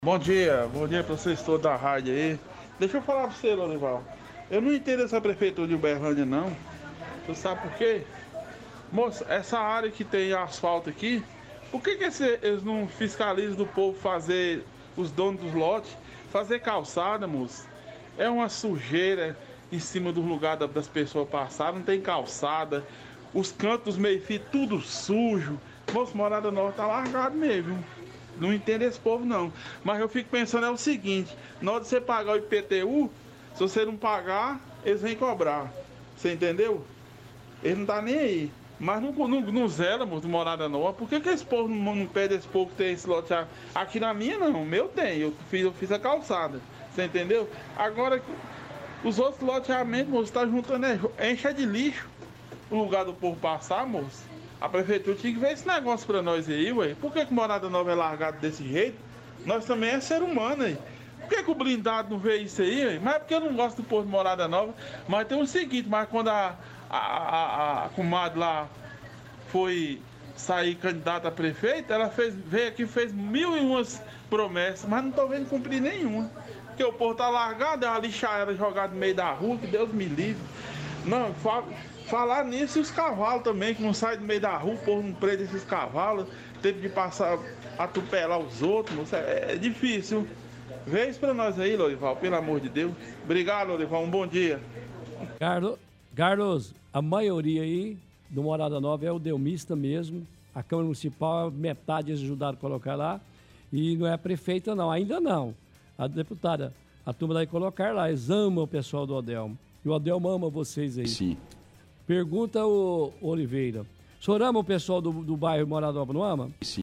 – Ouvinte diz que não entende obras da prefeitura no bairro Morada Nova.